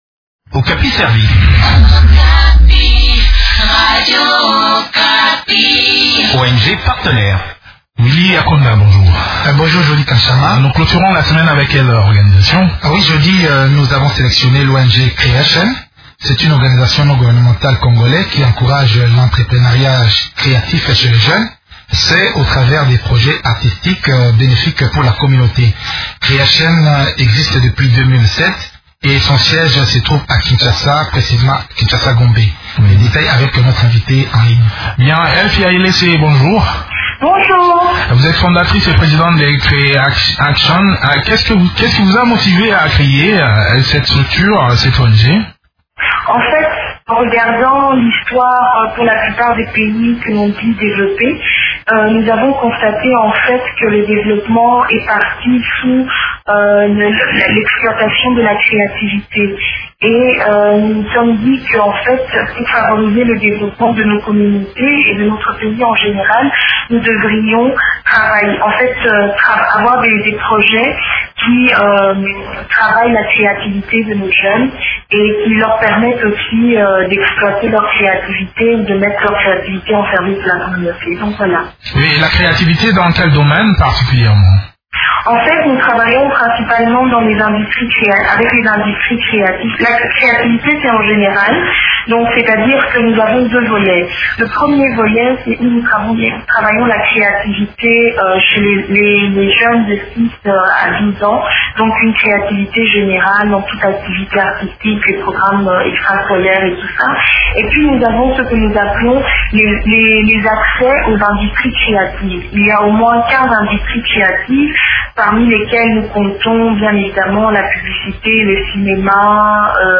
Echanges